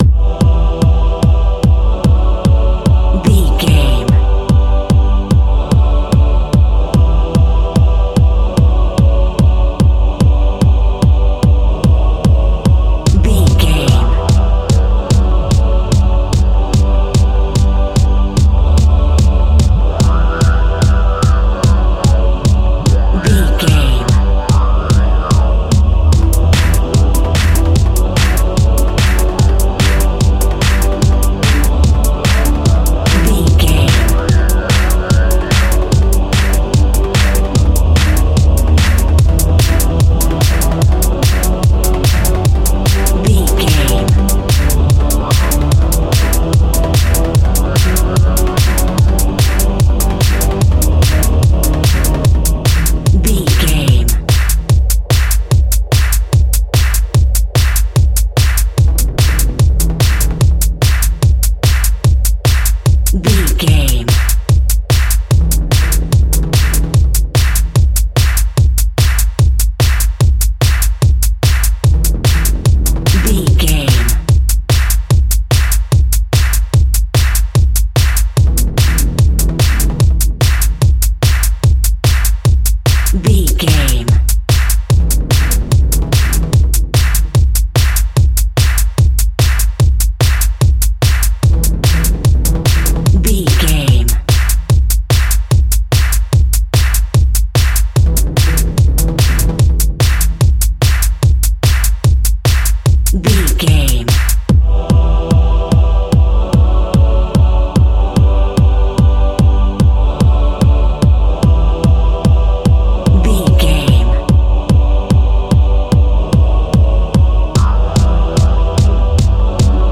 Aeolian/Minor
Fast
meditative
hypnotic
epic
dark
drum machine
synthesiser
uptempo
synth leads
synth bass